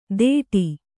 ♪ dēṭi